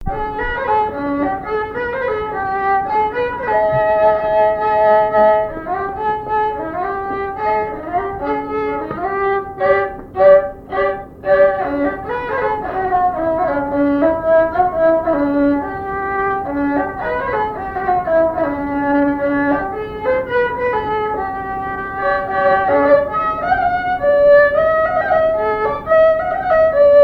danse : ronde : grand'danse
répertoire au violon et à la mandoline
Pièce musicale inédite